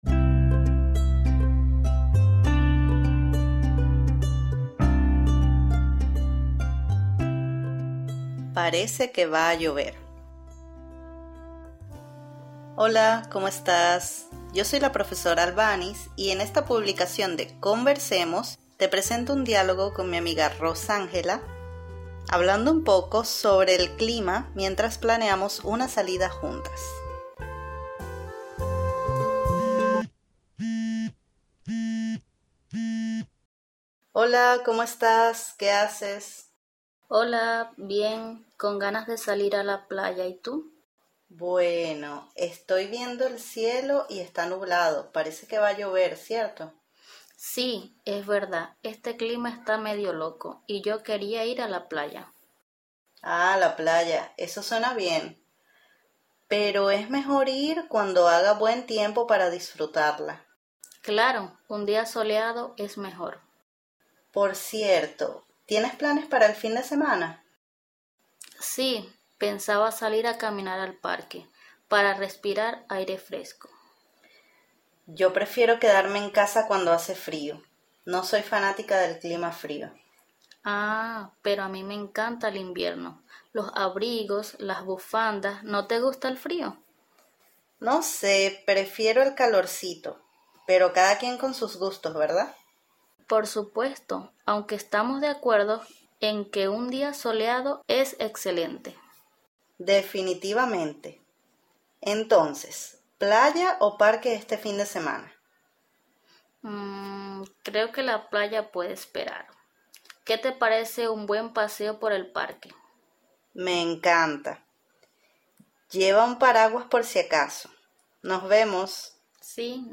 Diálogo: